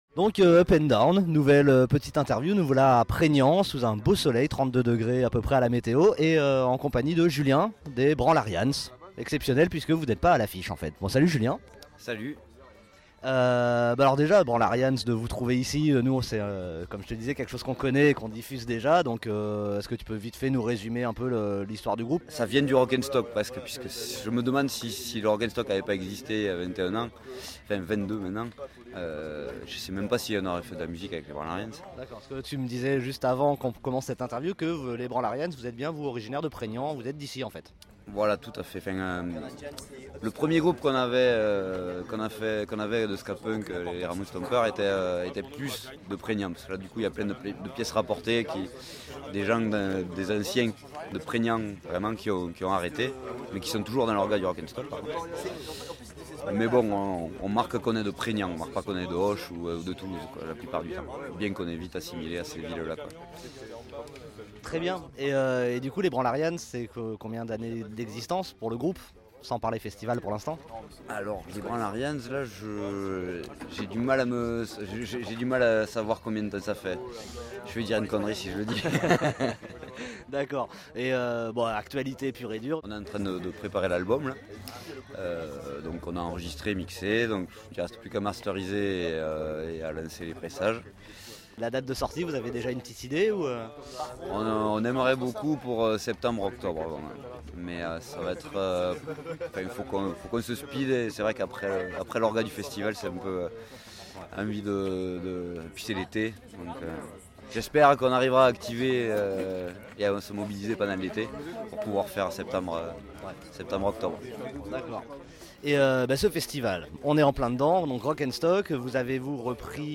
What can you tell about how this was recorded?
Festival Rock'n'Stock | Preignan | 5-7 juillet 2013